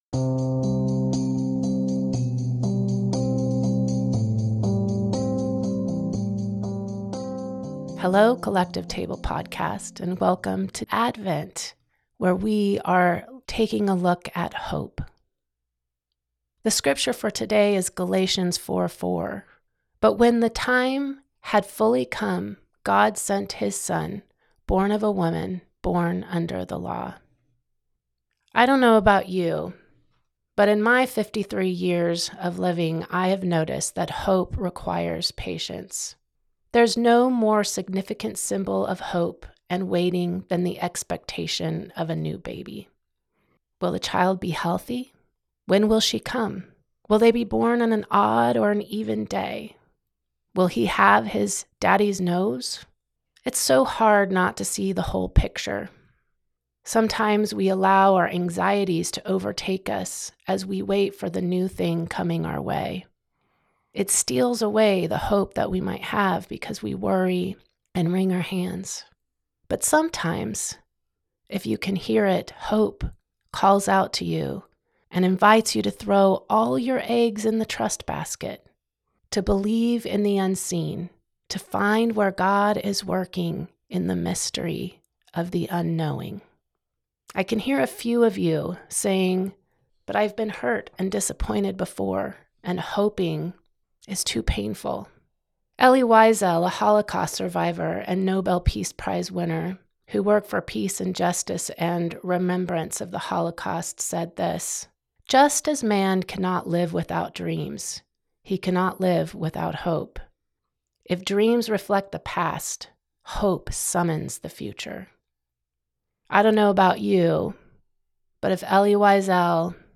Each week, the reflections will focus around a different theme of Advent: Hope, Peace, Joy, and Love. We hope these short meditations can be a meaningful part of your Advent practice.